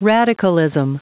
Prononciation du mot radicalism en anglais (fichier audio)
Prononciation du mot : radicalism